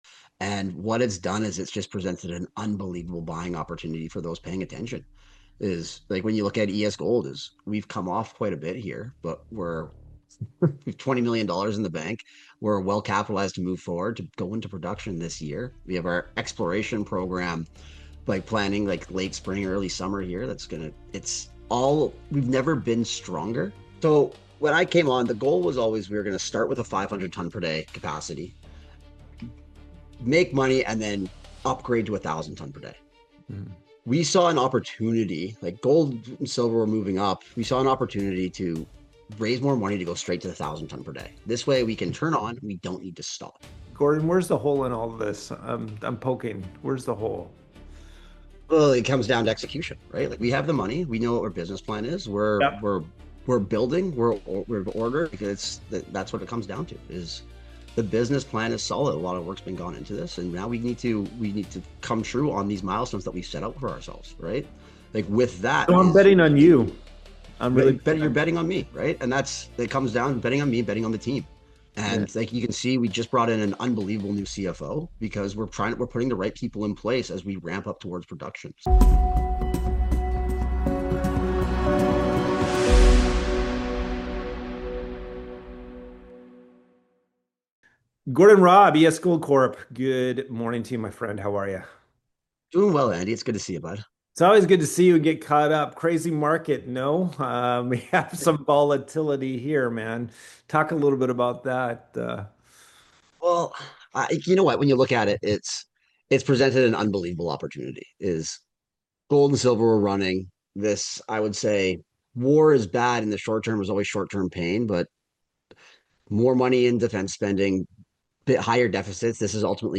The interview gets into the cash-flow-first model